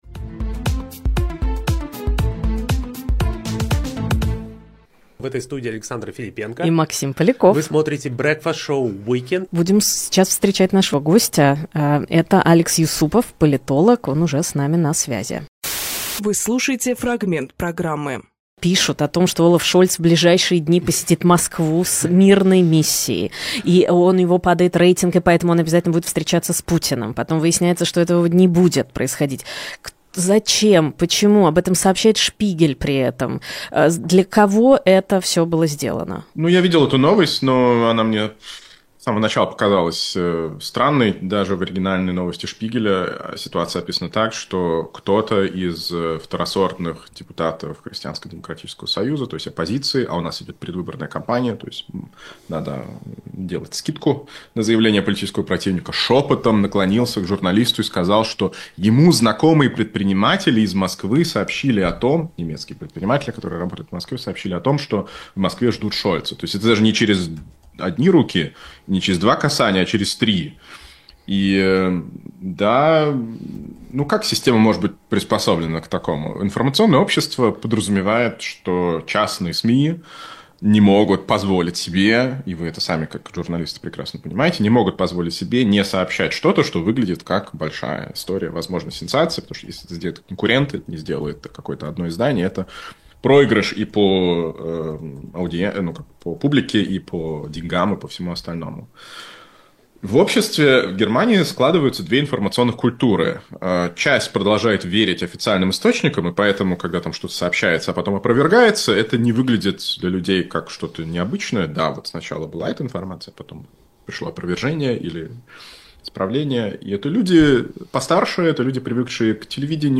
Фрагмент эфира от 22.12.24